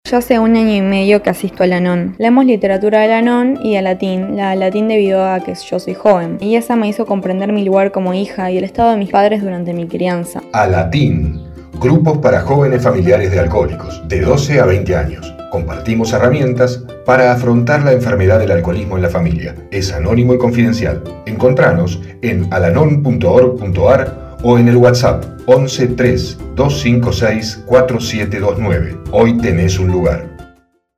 Spots de radio
Spot-radio-Alateen-2021.mp3